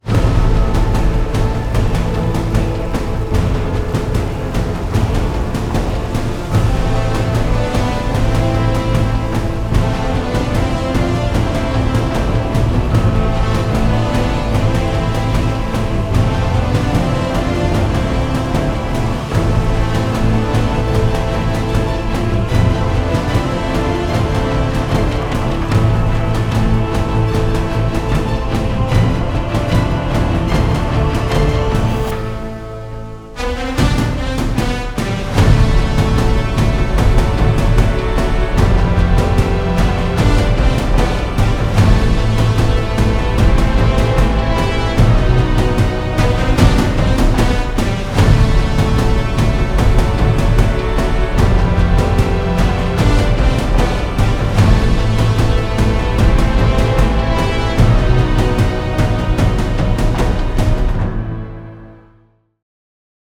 without dialogues and disturbances